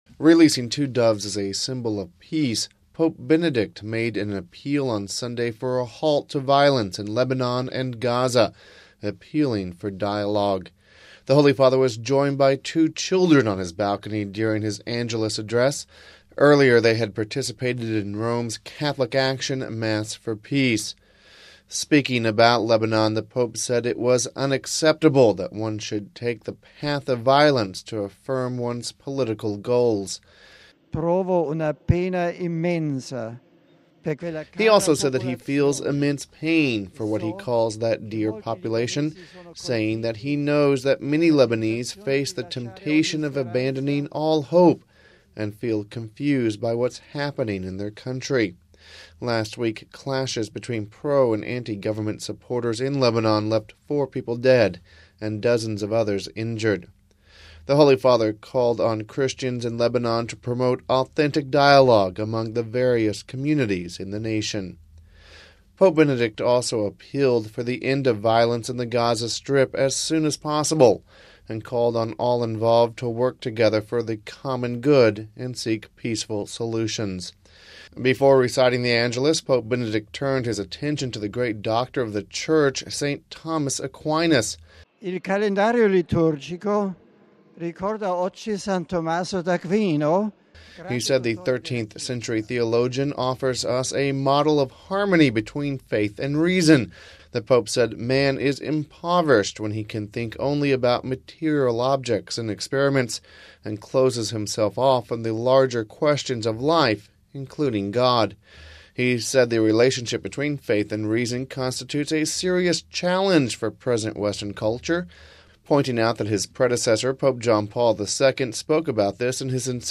this report